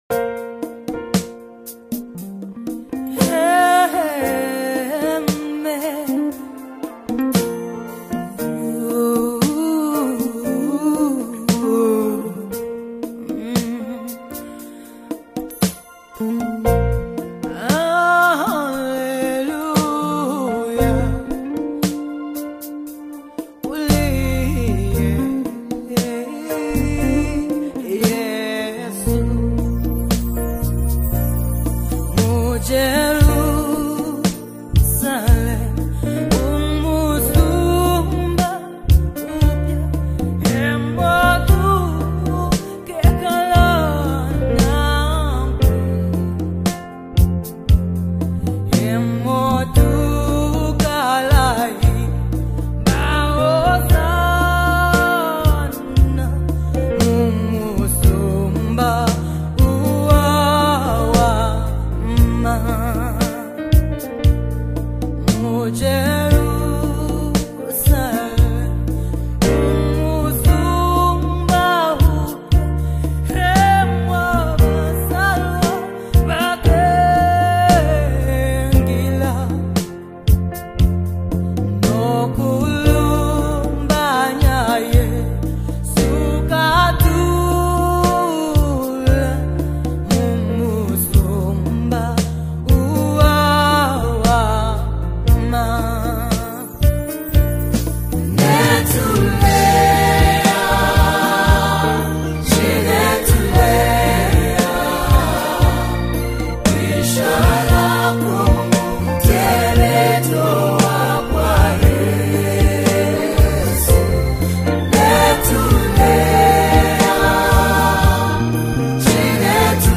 Best Classic Worship Song